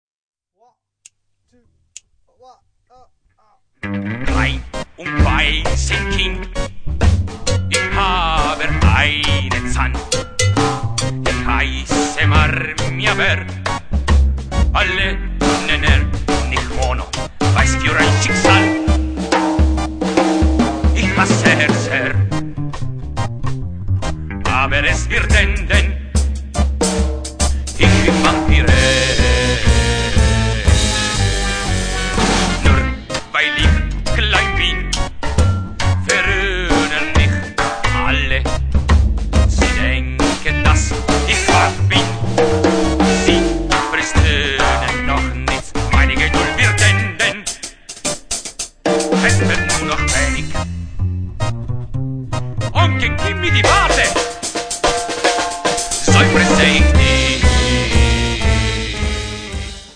in salsa reggae